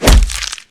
kick1.ogg